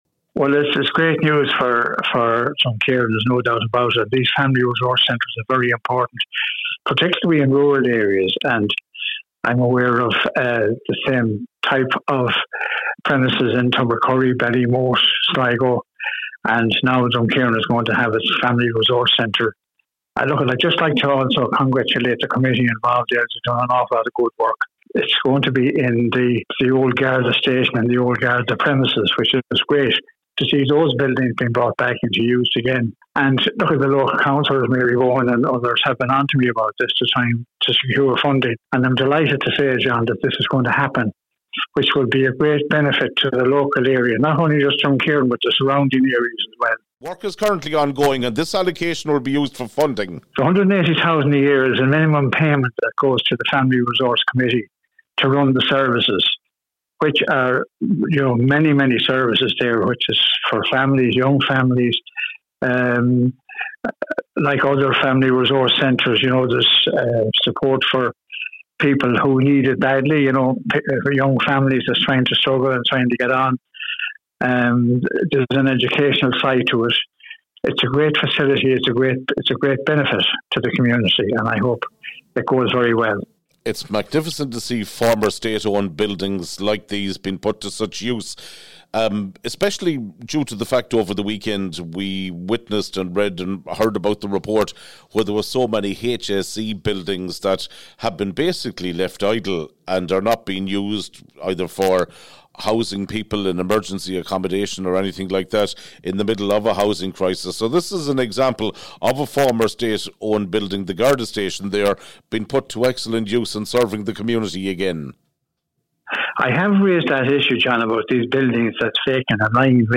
Genres: News